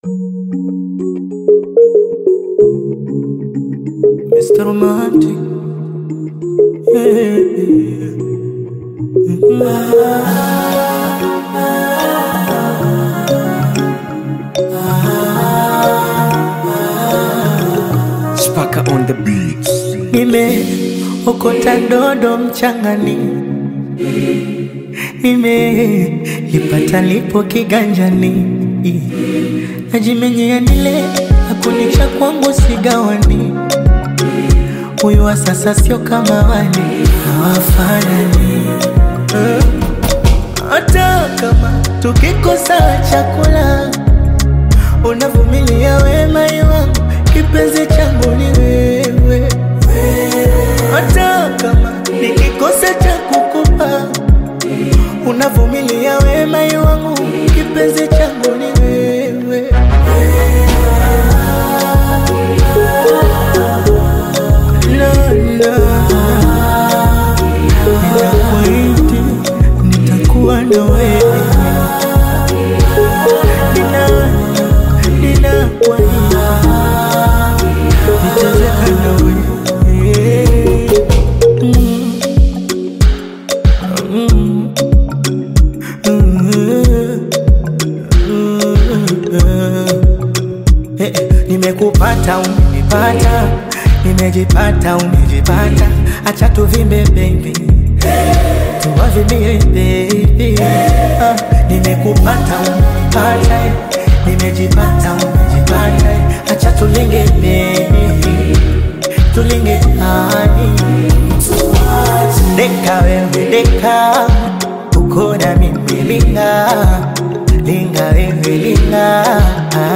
smooth Afro-Pop single
Genre: Bongo Flava